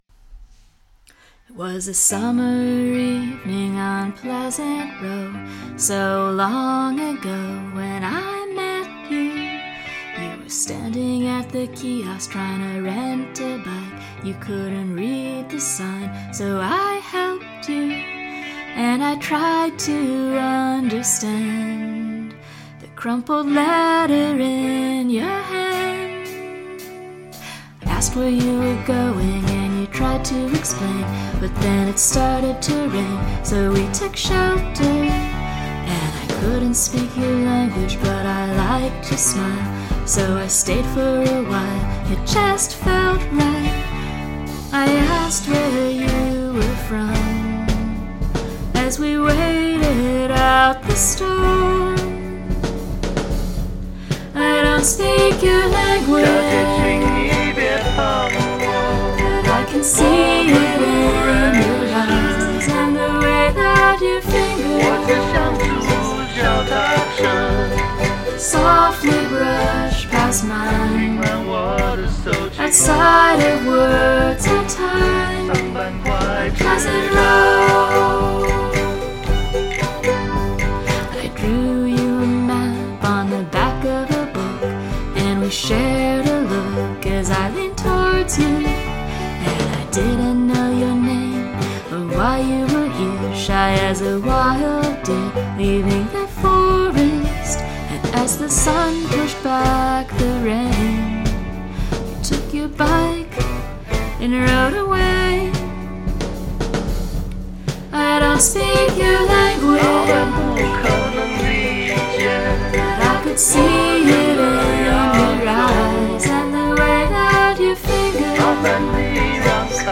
Must include a guest singing or speaking in another language
Your vocal is sweet even on shitty work speakers.